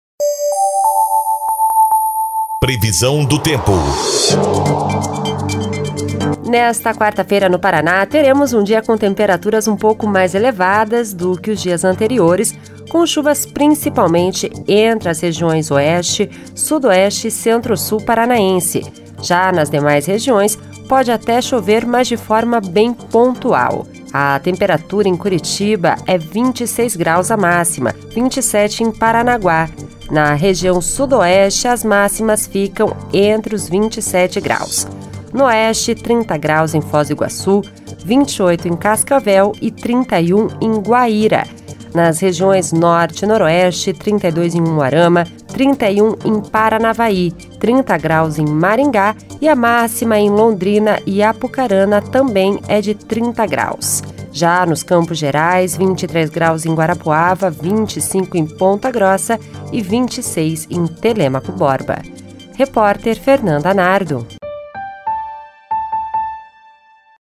Previsão do tempo (23/03)